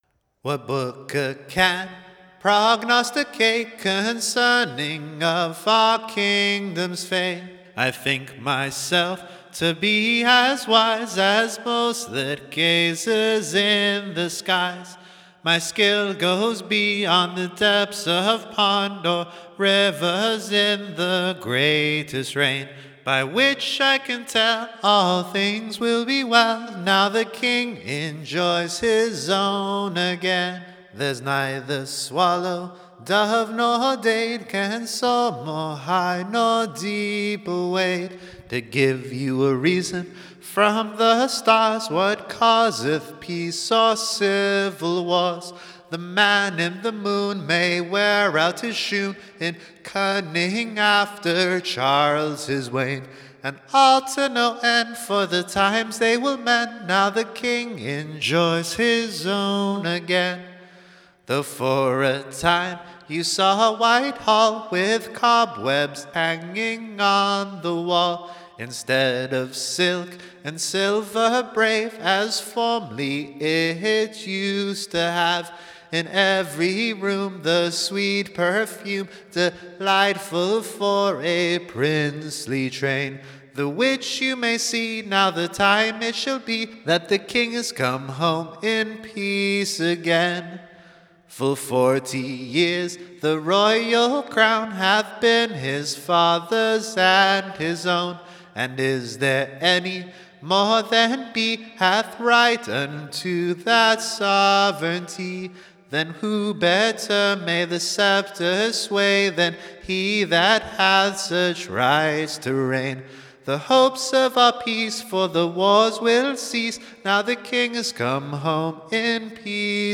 EBBA 31778 - UCSB English Broadside Ballad Archive